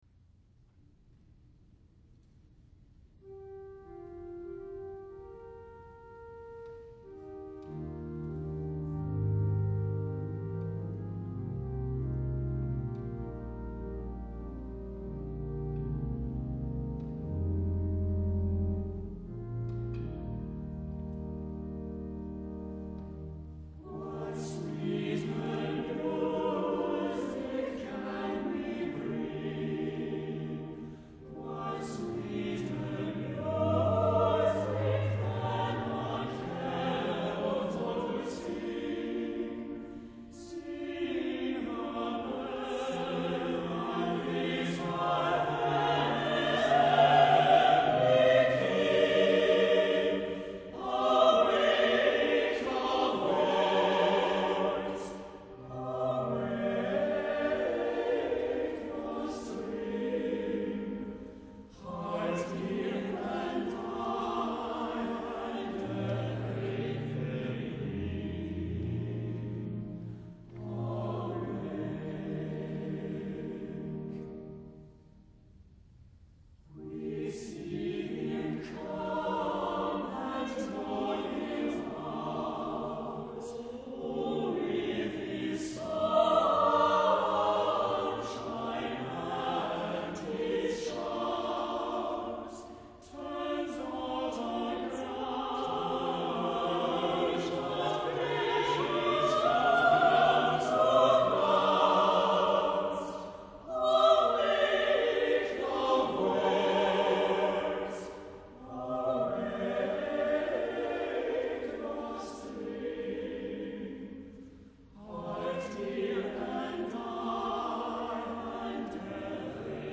* harmonies give color to this verse anthem